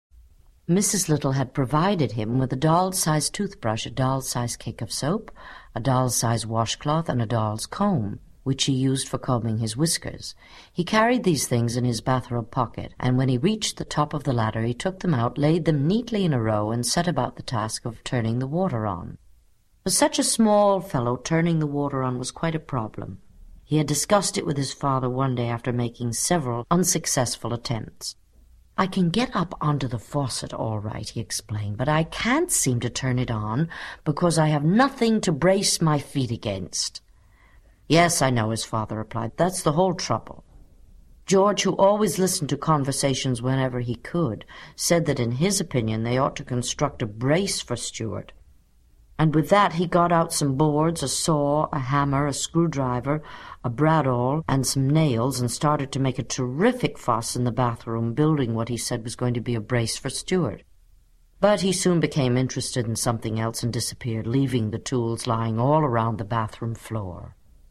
在线英语听力室精灵鼠小弟 第11期:半途而废的乔治的听力文件下载, 《精灵鼠小弟》是双语有声读物下面的子栏目，是学习英语，提高英语成绩的极好素材。